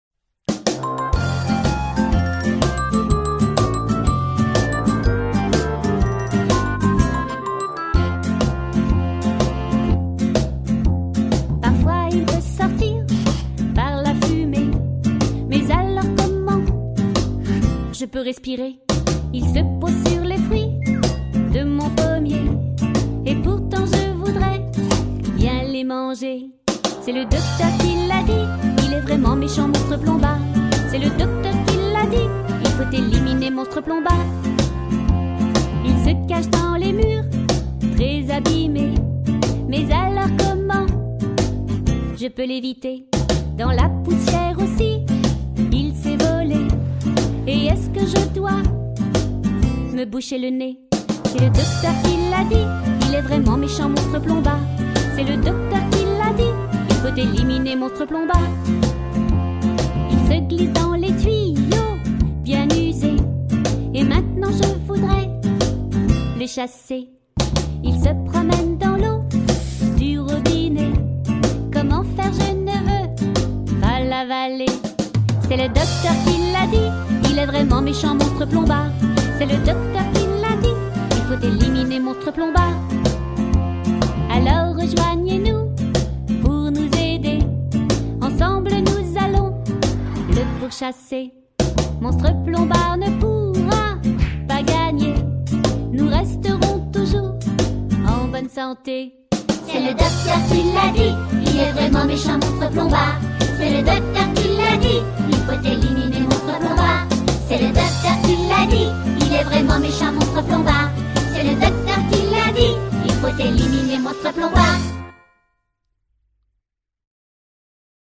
Enjouée, entraînant https